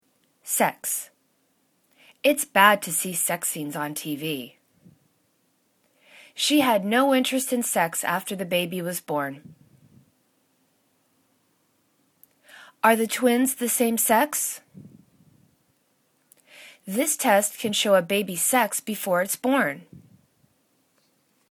sex   /seks/ [U]